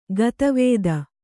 ♪ gata vēda